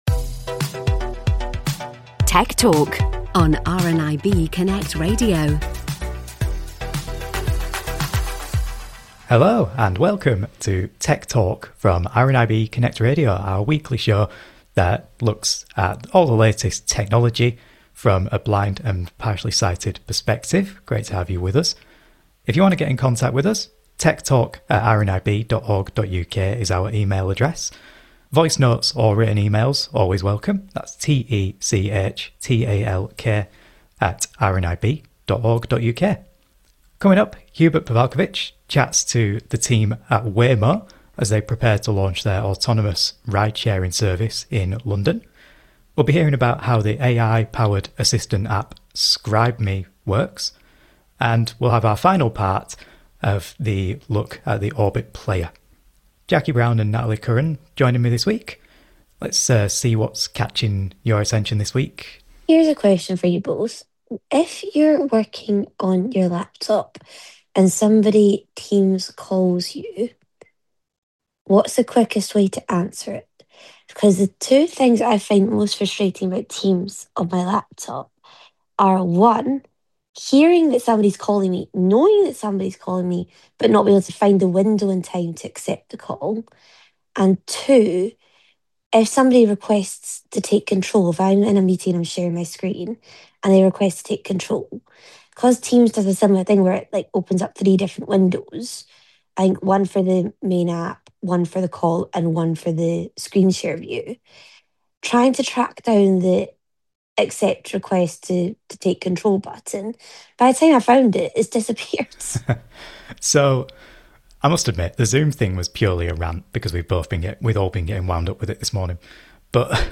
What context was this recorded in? You can listen to the show live in the UK every Tuesday at 1pm on Freeview Channel 730, online at RNIB Connect Radio | RNIB , or on your smart speaker.